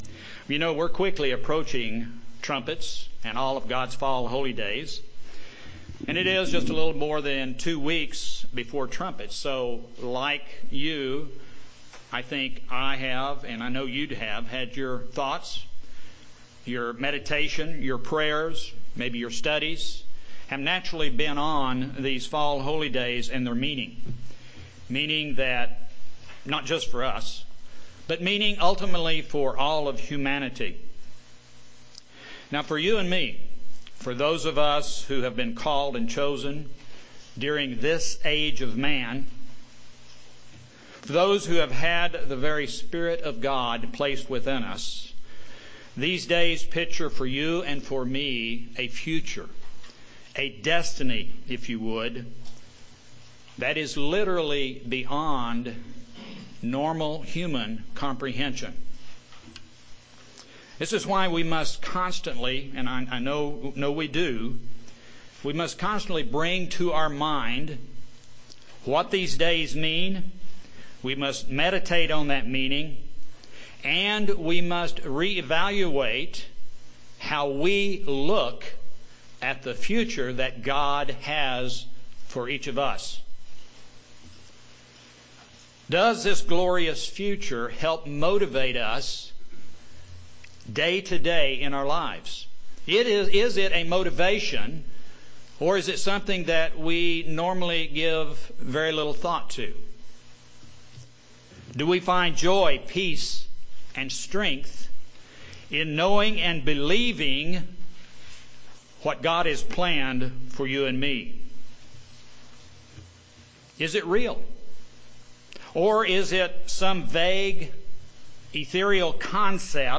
Given in Kansas City, KS
UCG Sermon Studying the bible?